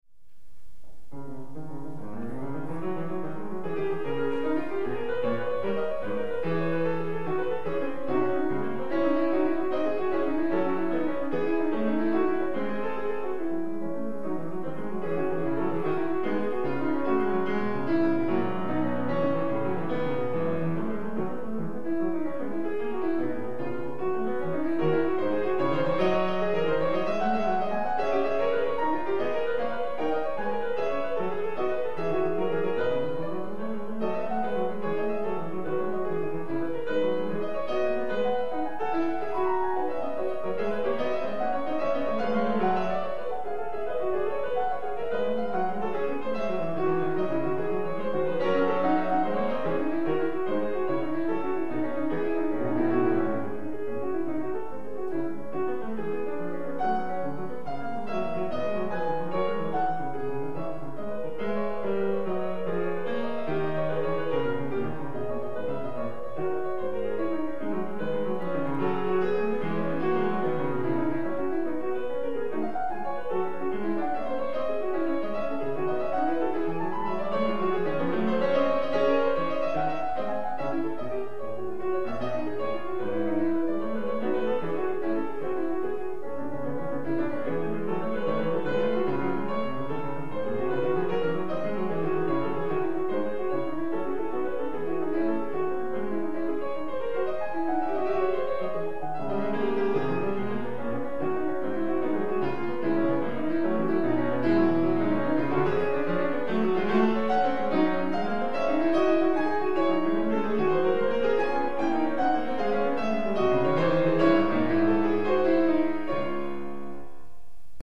PIANIST & PAINTER
SOLO
icon_13.gifFuga in cis-moll/do diese mineur/c sharp minor,